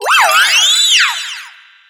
Cri de Créfollet dans Pokémon X et Y.